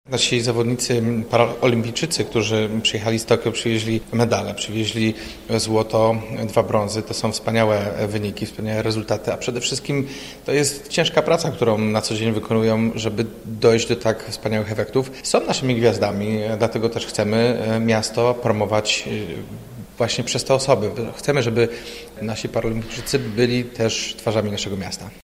Mówi Jacek Wójcicki: https